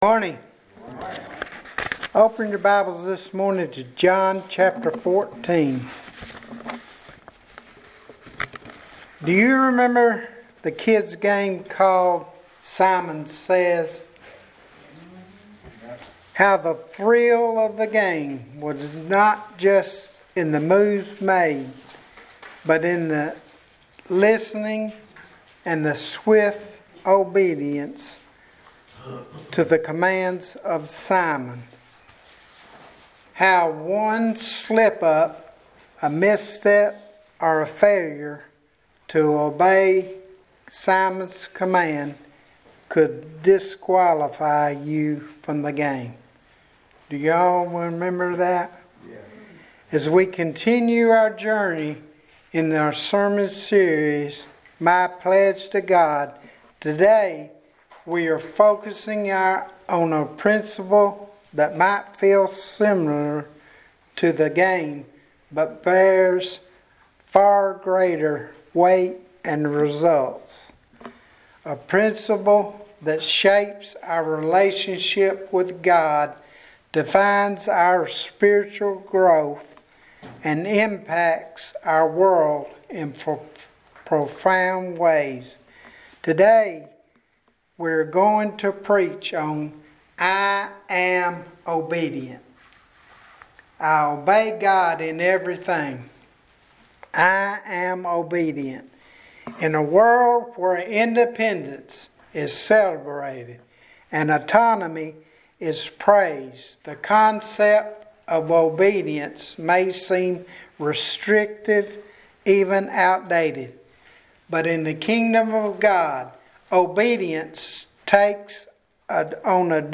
2023 Sermons - IHS Ministries
Sermon-Series-My-Pledge-to-God-Message-I-am-Obedient.MP3